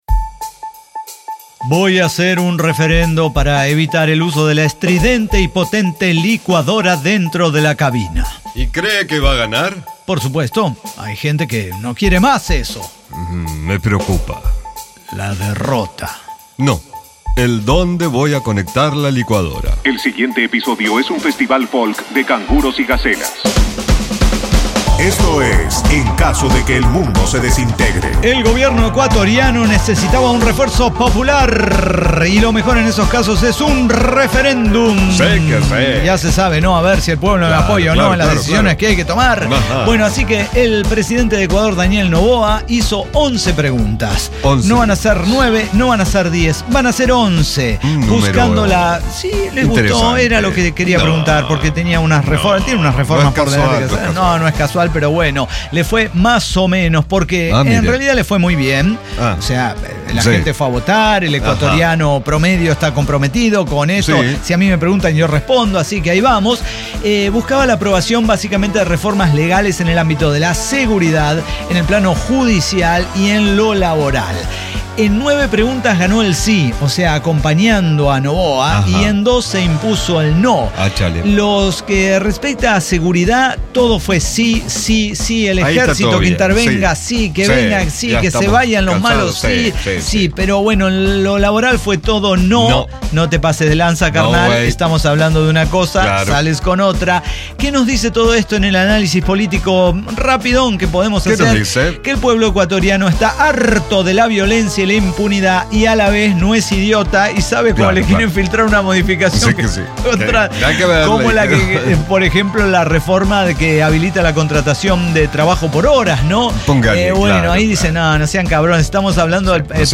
El Cyber Talk Show